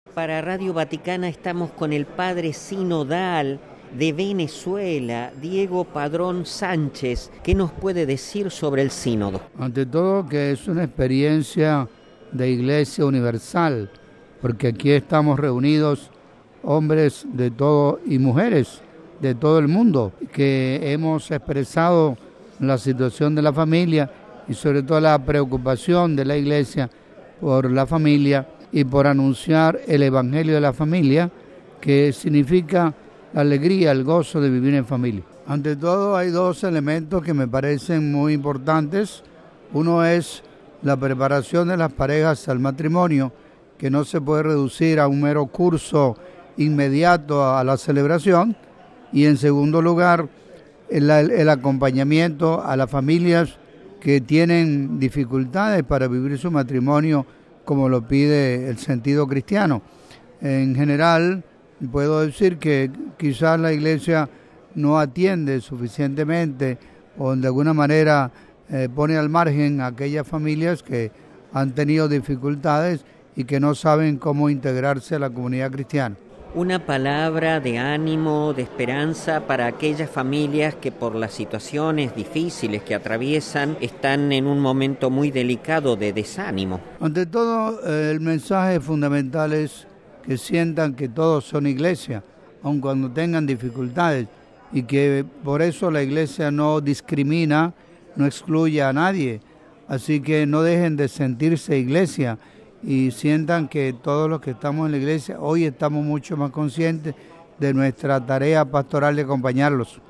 “La alegría y el gozo de vivir en familia”, Mons. Padrón obispo venezolano